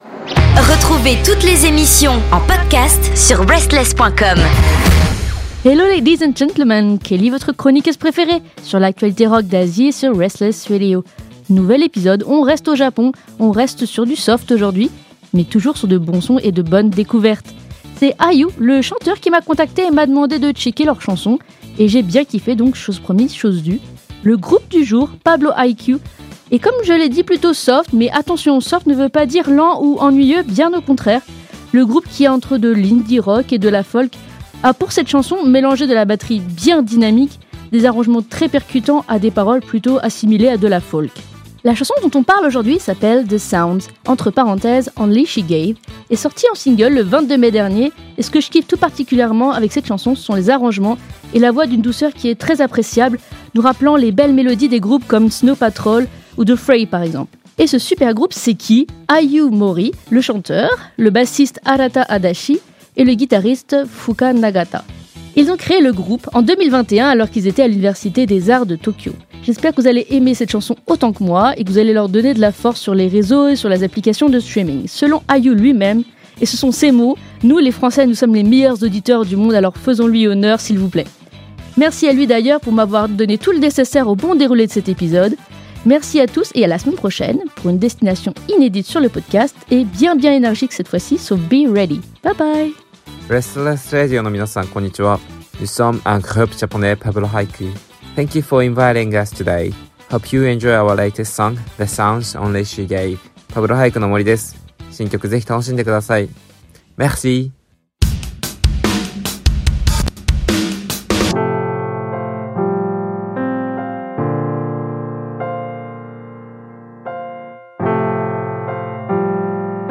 Pour cette semaine on reste au Japon avec un super groupe entre folk et indie-rock : Pablo Haiku. Travail très intéressant sur l'instru sur ce nouveau single : The Sounds (only she gave), c'est ma recommandation de la semaine.